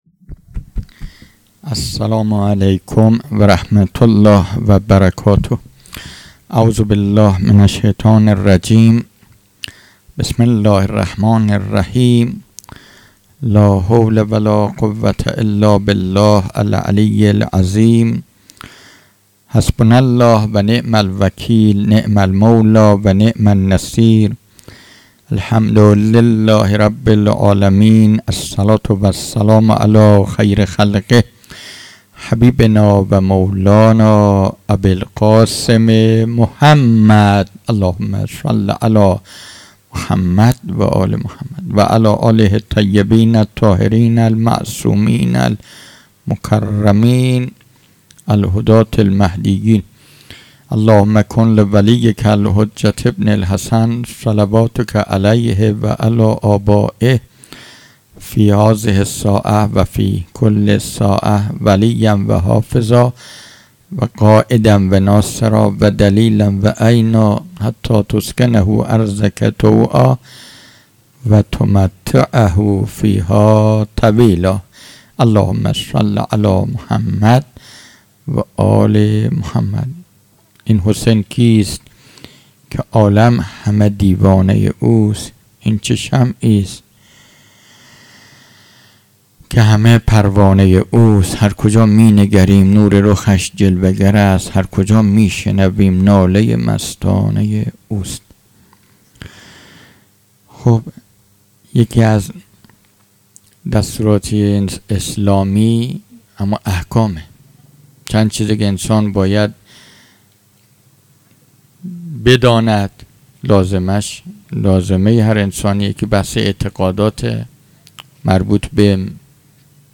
خیمه گاه - هیئت بچه های فاطمه (س) - پیش منبر
دهه اول محرم الحرام ۱۴۴٢ | شب ششم